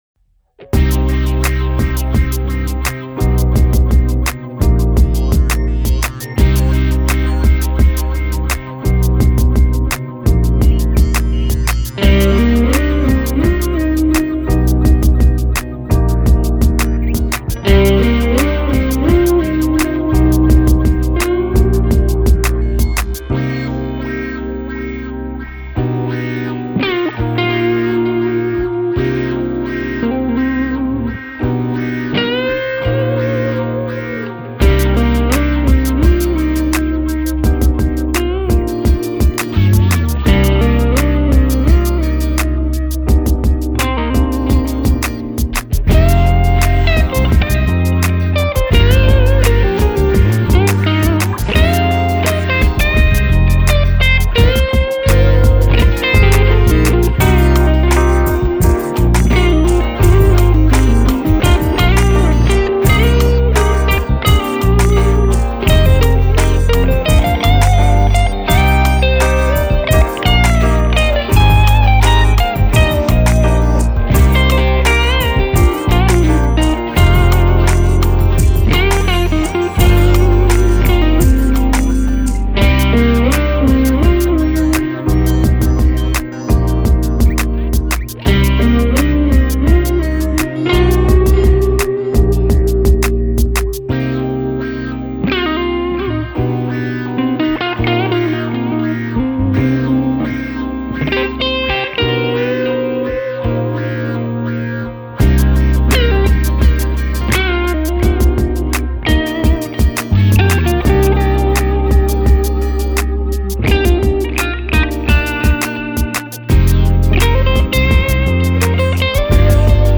Und das habe ich dann versucht am Freitag, als ich meinen Jambeitrag aufgenommen habe, umzusetzen.